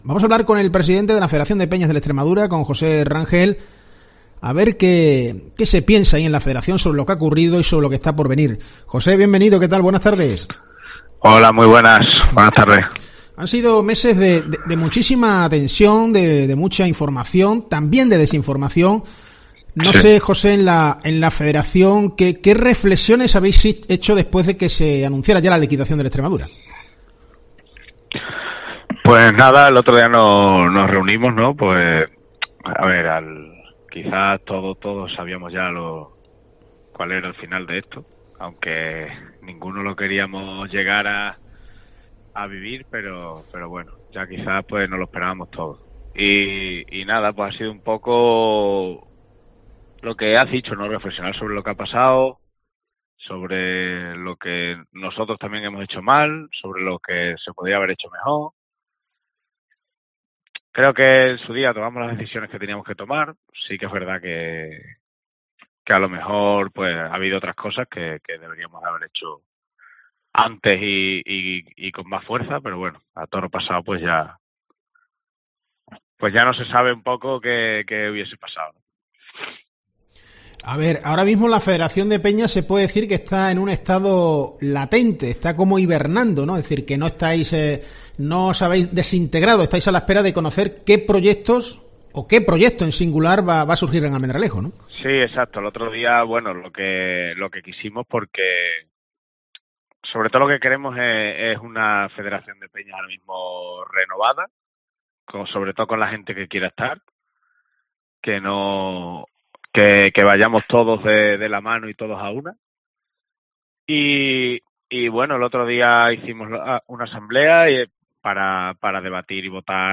Estas son las frases más significativas de la entrevista: